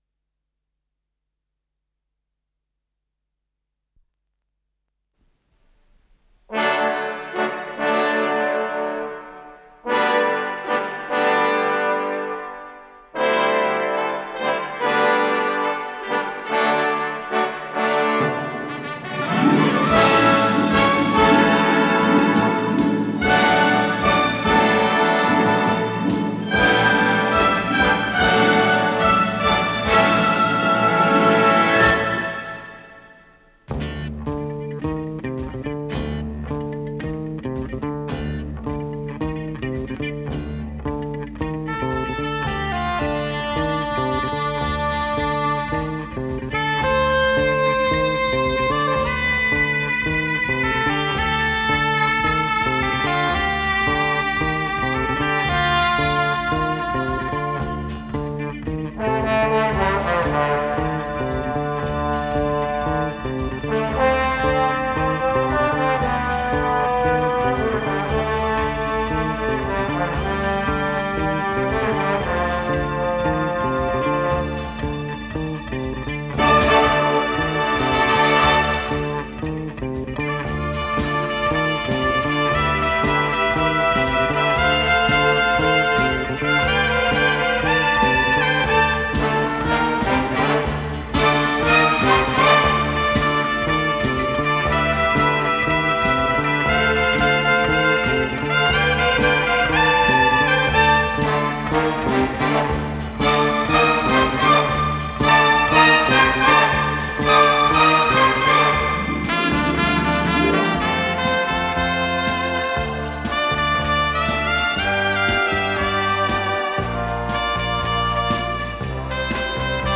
с профессиональной магнитной ленты
ре минор